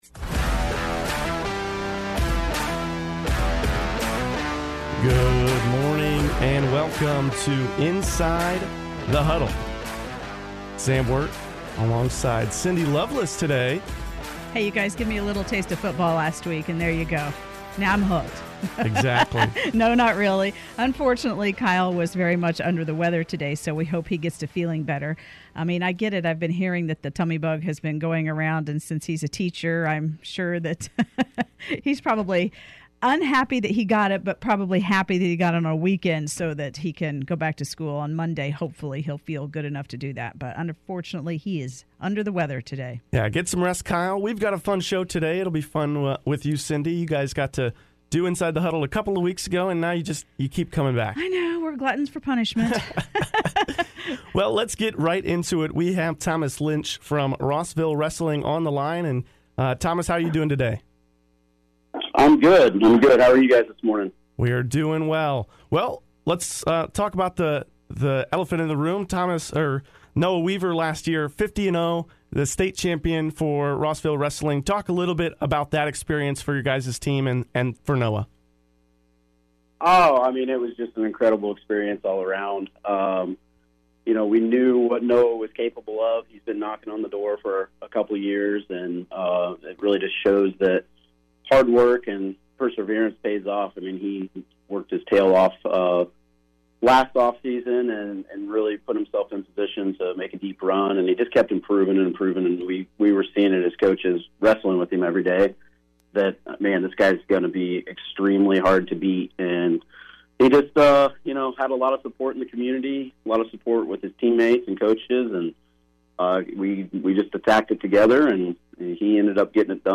full interview